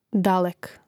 dàlek dalek